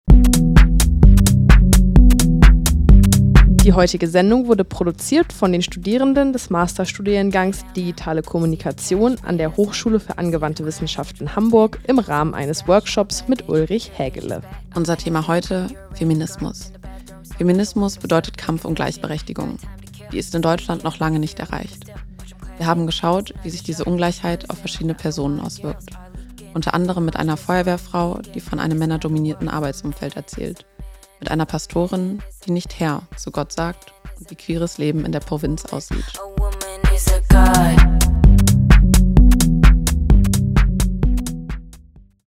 Diese Sendung wurde produziert im Rahmen eines Hörfunkworkshops an der Hochschule für Angewandte Wissenschaften Hamburg von Studierenden des Masterstudiengangs Digitale Kommunikation.